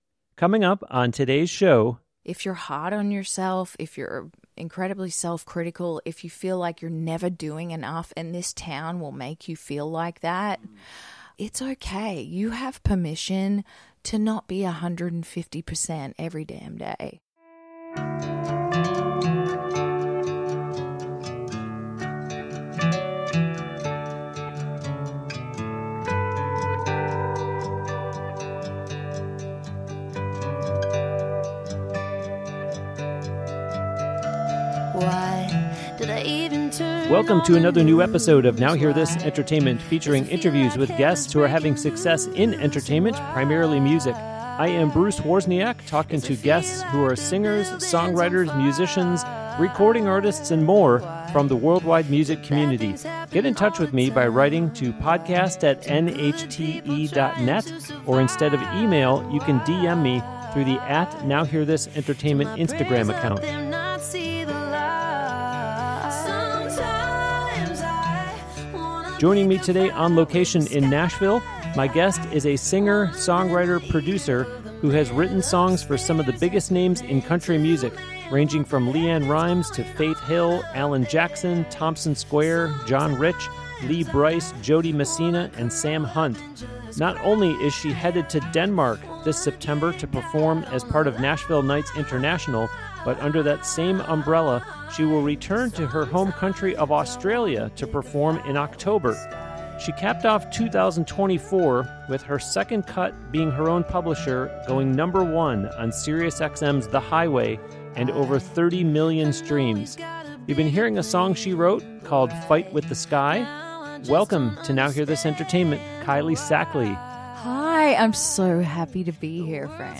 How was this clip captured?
On-location interview with a singer, songwriter, guitar player who just released a new album last month, on January 10th.